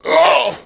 pain2.wav